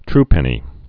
(trpĕnē)